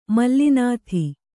♪ malli nāthi